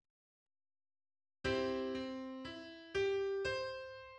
Arpeggiation, the first technique of composing-out.[23]
Auskomponierung_arpeggiation.mid.mp3